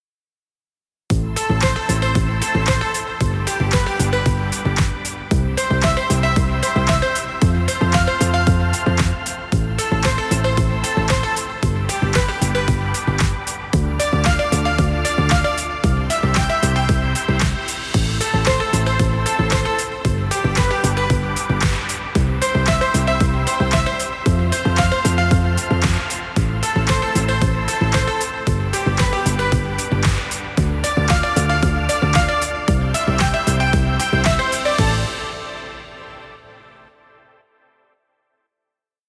dance track with nice beats.
House music for video. Background Music Royalty Free.